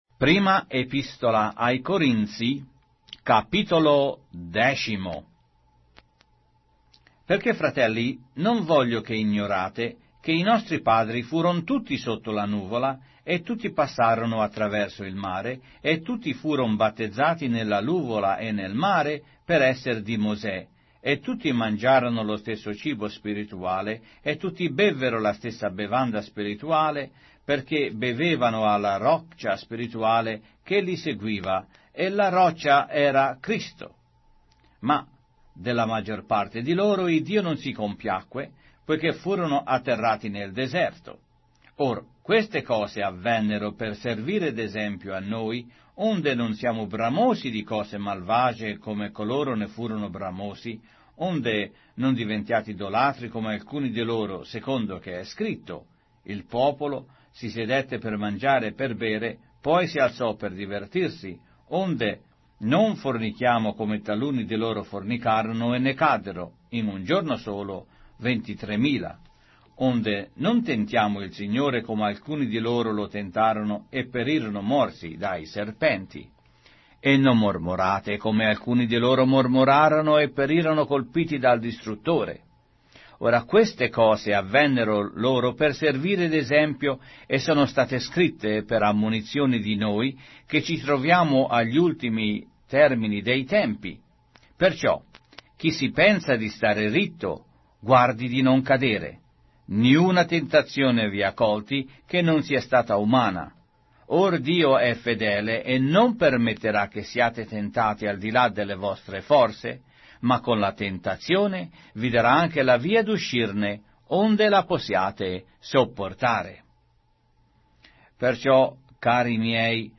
Sacra Bibbia - Riveduta - con narrazione audio - 1 Corinthians, chapter 10